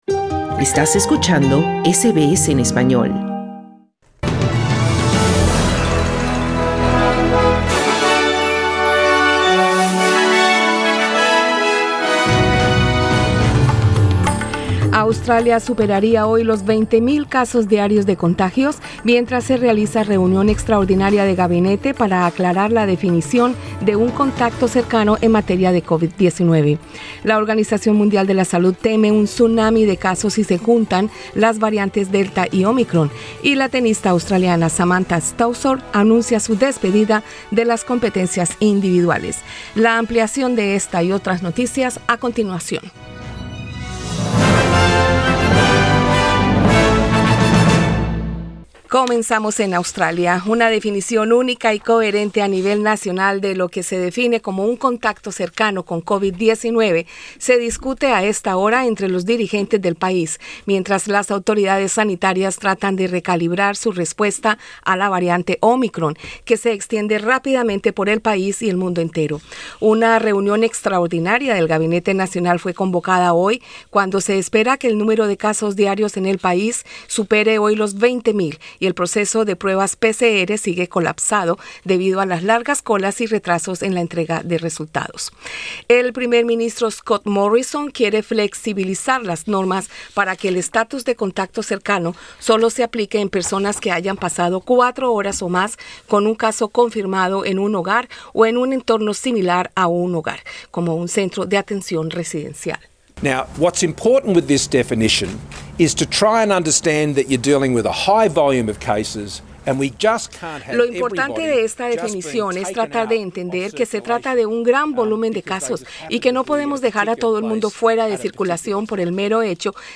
Escucha el boletín de noticias pulsando arriba en la imagen principal.